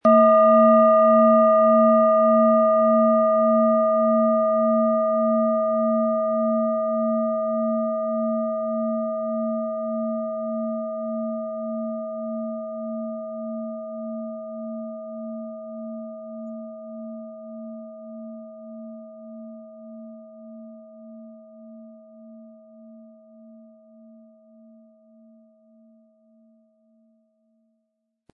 Alphawelle
• Tiefster Ton: Mond
MaterialBronze